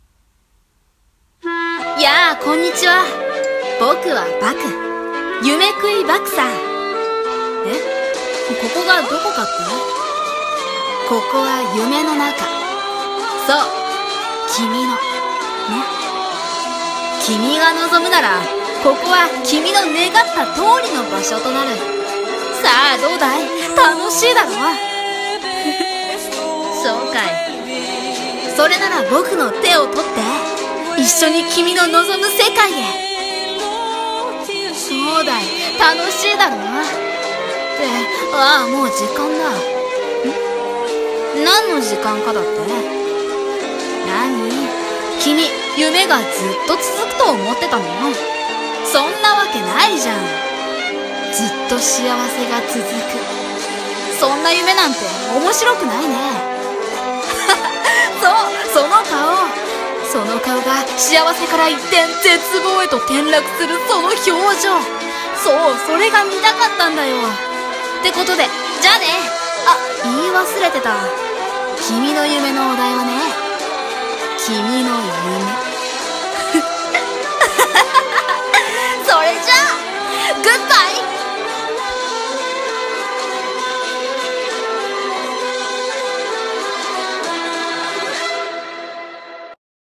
【声劇】夢喰い白黒バク【朗読】